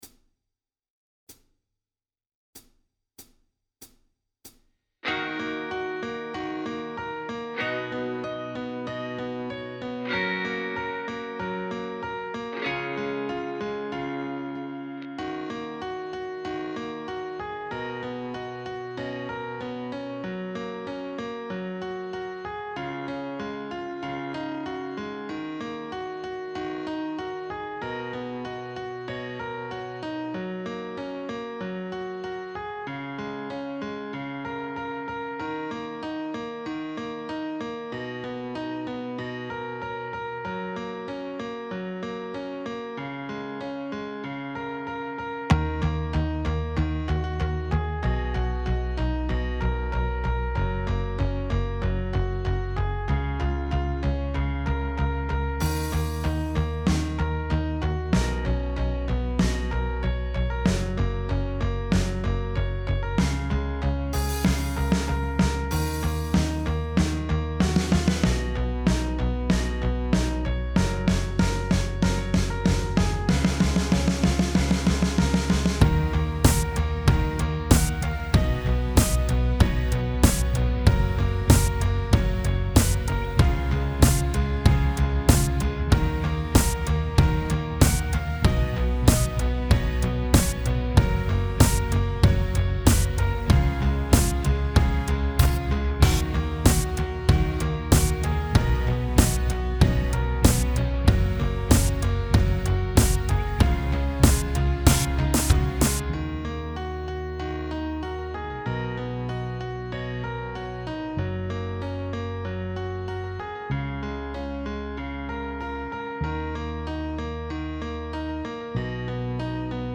Backing Track: